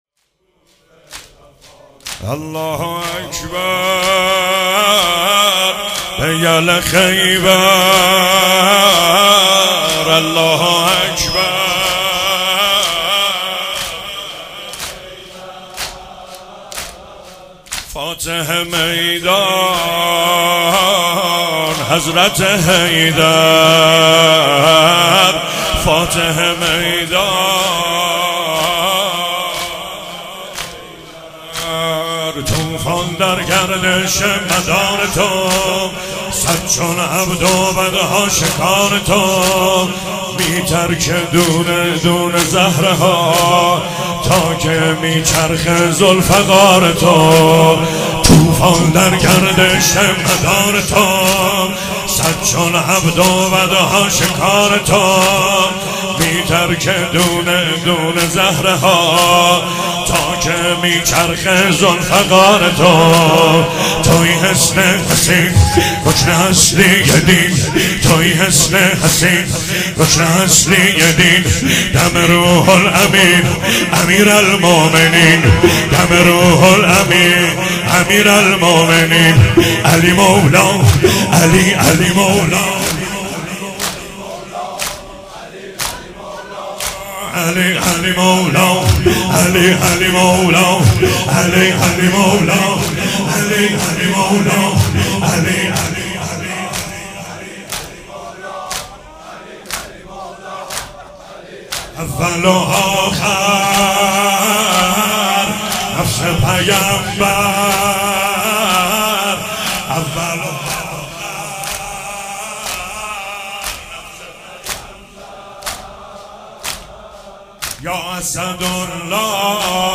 چهاراه شهید شیرودی حسینیه حضرت زینب (سلام الله علیها)
زمینه- کوفه وفا ندارد...